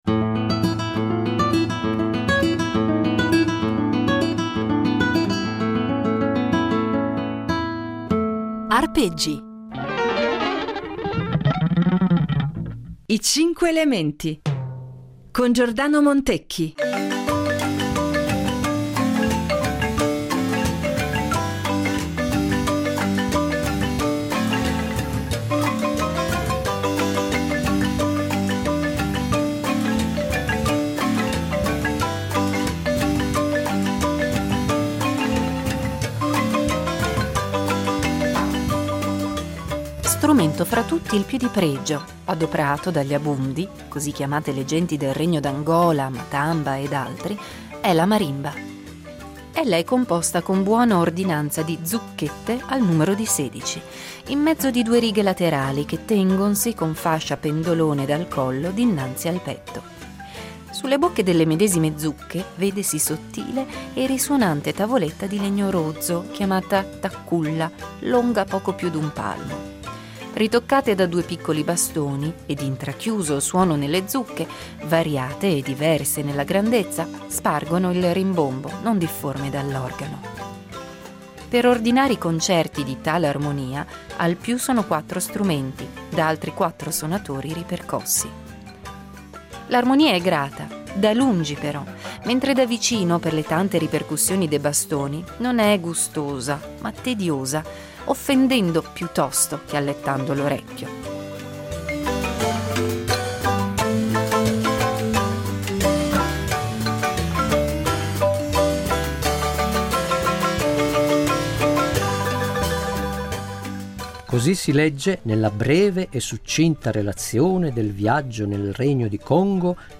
Marimba (8./10)